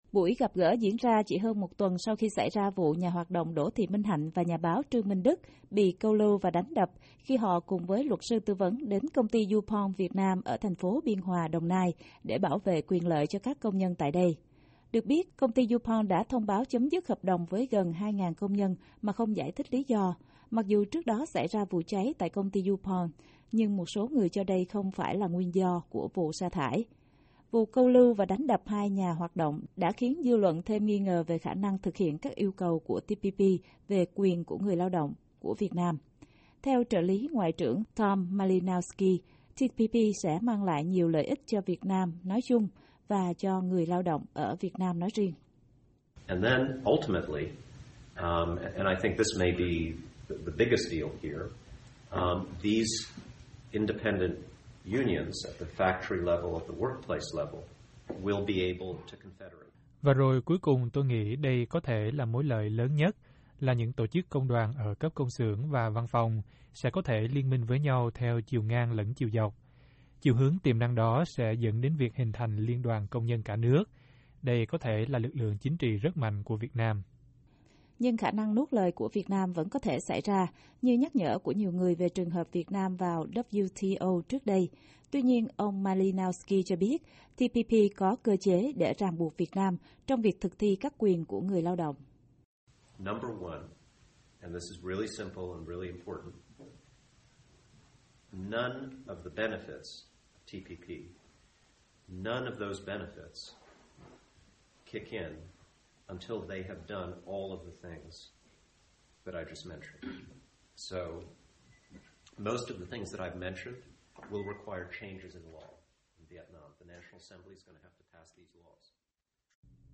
Trong lúc Hiệp ước Đối tác Xuyên Thái Bình Dương (TPP) đang trong thời gian chờ được thông qua ở quốc hội Mỹ, nhiều người lo ngại lịch sử của WTO sẽ tái diễn khi Việt Nam không tuân thủ các yêu cầu về nhân quyền, về quyền của người lao động một khi được chính thức là thành viên. Trợ lý Ngoại trưởng Mỹ đặc trách dân chủ, nhân quyền và lao động, ông Tom Malinowski, giải đáp một số thắc mắc trong cuộc gặp gỡ với cộng đồng Việt Nam ở thủ đô Washington hôm 4/12.